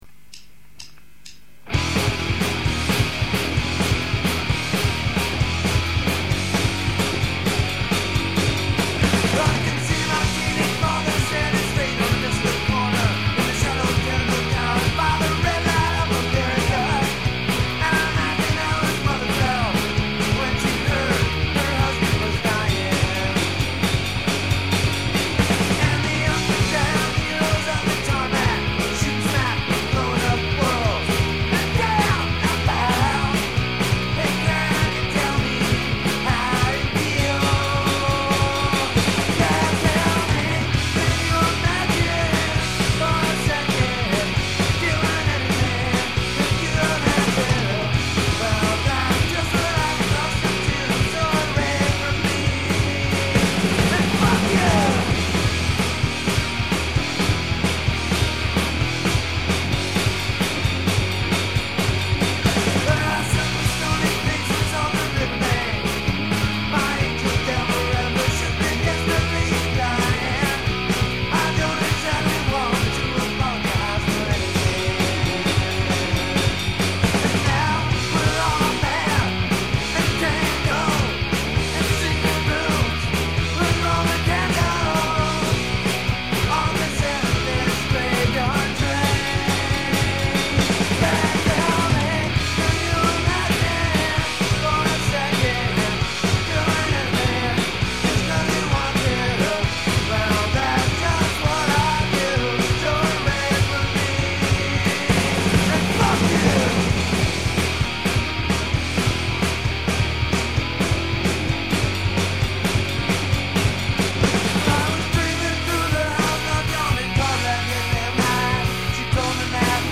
home demos